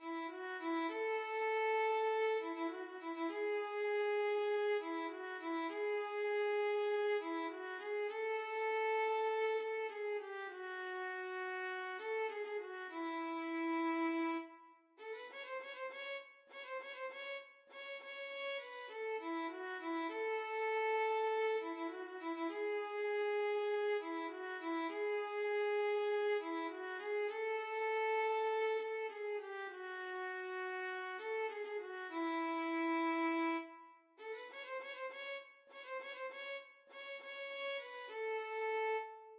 Childrens Childrens Violin Sheet Music Down By The Bay
Quick two in a bar = c.100
A major (Sounding Pitch) (View more A major Music for Violin )
Violin  (View more Easy Violin Music)
Traditional (View more Traditional Violin Music)
down_by_the_bayVLN.mp3